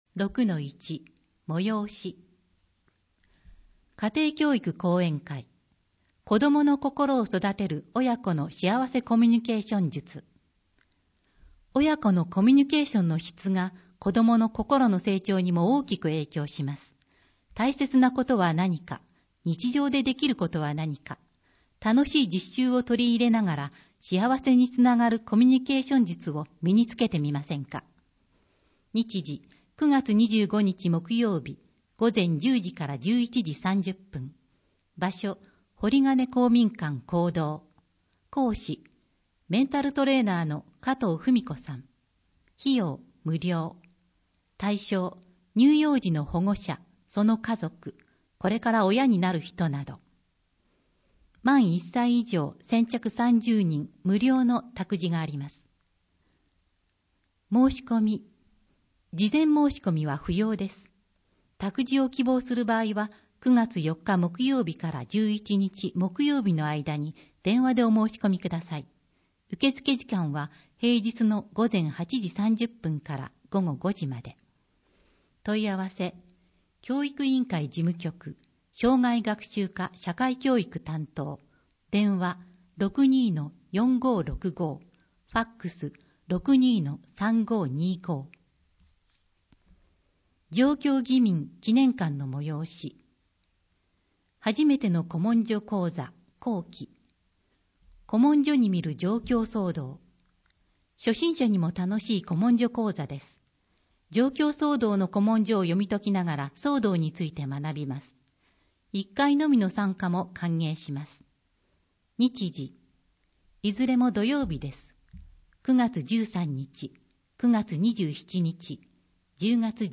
広報あづみの朗読版192号(平成26年9月3日発行) - 安曇野市公式ホームページ
この録音図書について